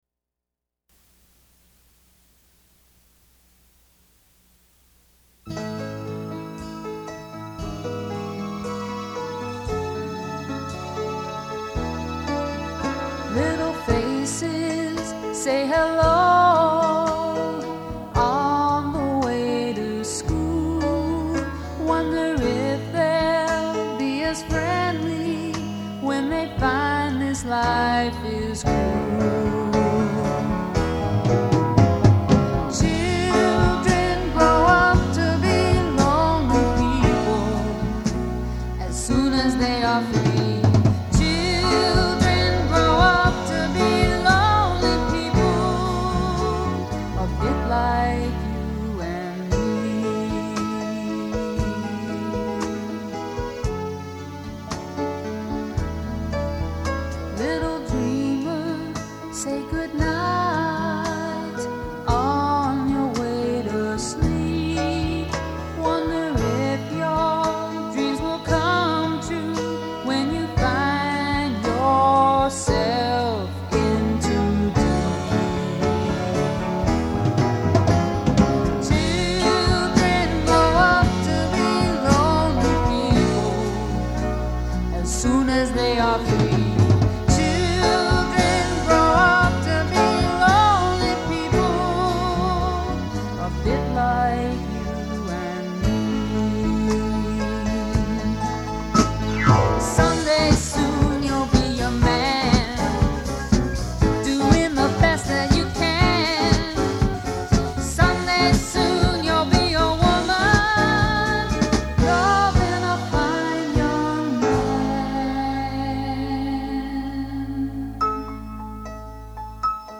the Jacques Brel-like song
"Children" (female) "Children" (male)
09_Children_female_mp3.mp3